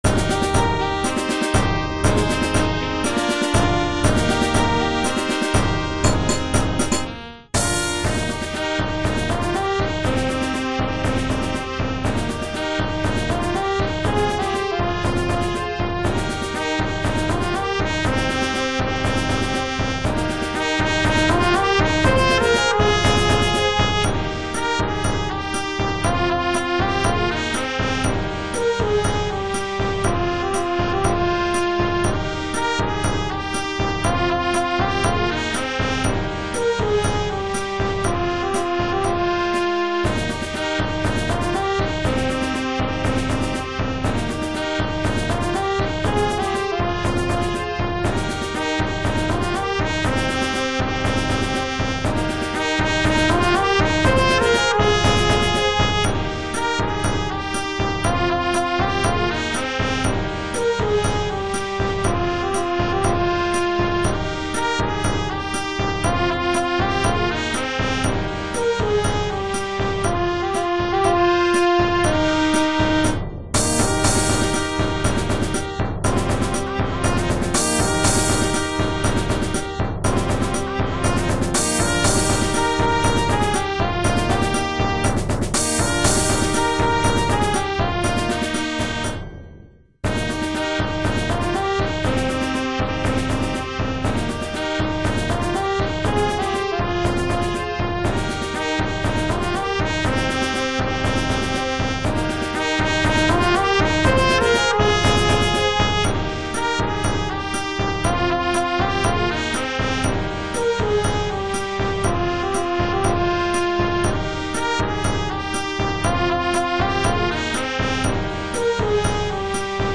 Versions available for small and larger student brass band.